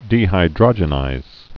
(dēhī-drŏjə-nīz, dē-hīdrə-jə-)